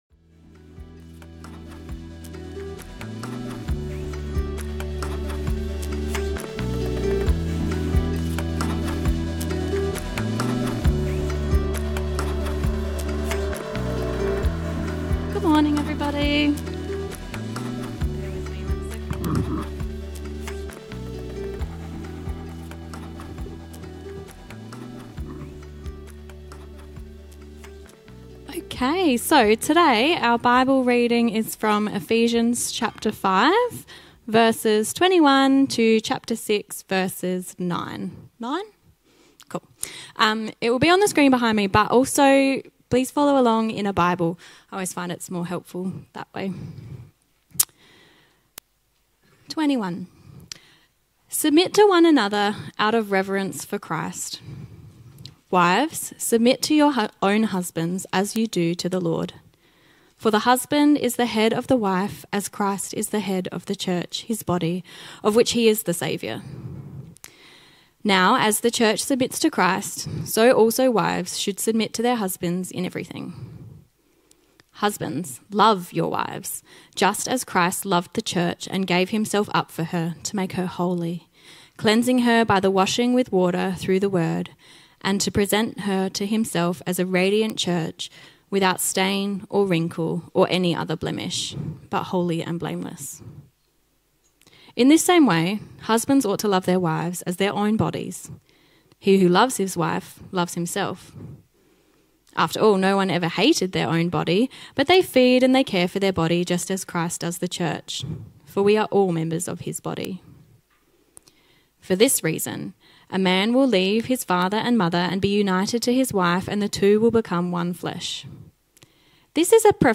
The bible reading and subject of today’s sermon is Ephesians 5:21–6:9, emphasizing mutual submission, love, and respectful relationships: wives submitting to husbands as the church submits to Christ, husbands loving wives as Christ loved the church, children obeying parents, and slaves obeying masters.